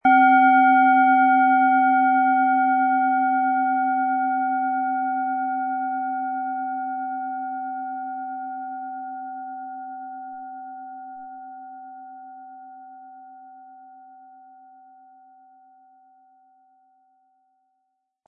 Handgearbeitete DNA Klangschale.
Lassen Sie die Klangschale mit dem kostenlosen Klöppel sanft erklingen und erfreuen Sie sich an der wohltuenden Wirkung Ihrer DNA.
MaterialBronze